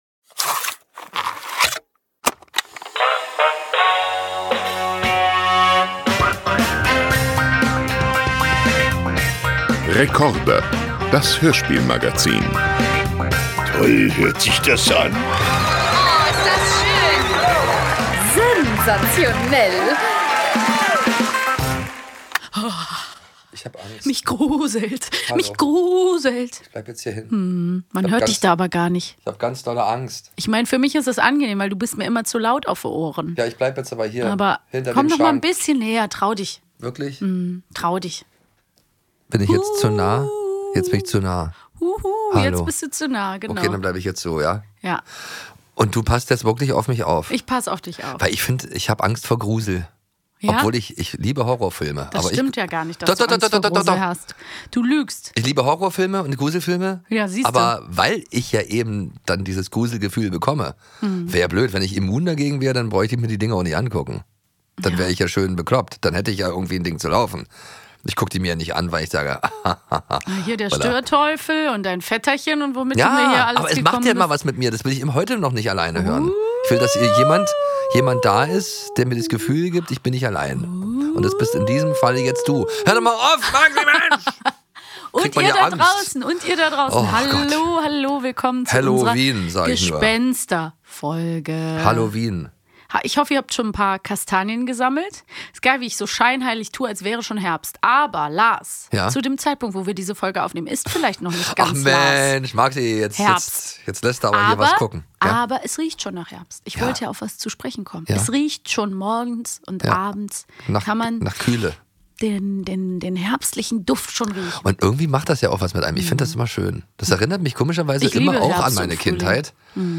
Heute spukt es im Podcaststudio, denn Halloween steht kurz vor der Tür.